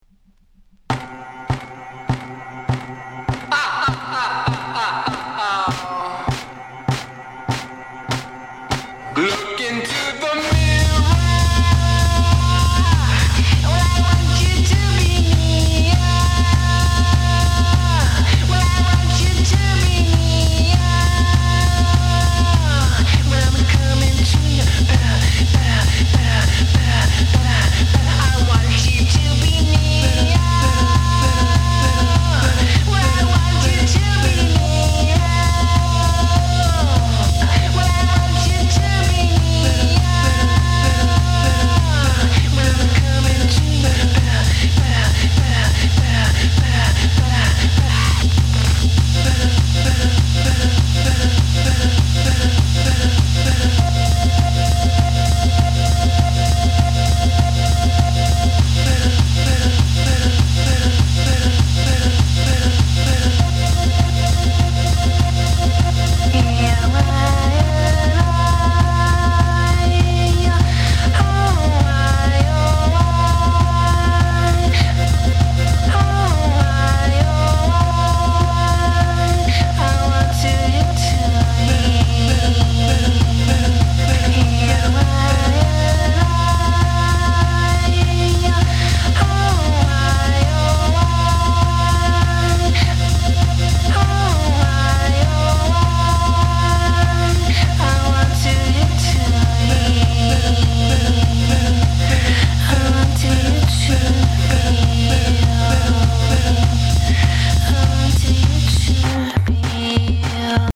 NO WAVE、ジャンク、アヴァンギャルドを軸に広義におけるダンスミュージックを集約したかのような意欲作。